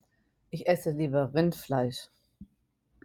(Ich ESS-e LIEB-er RIND-fleisch)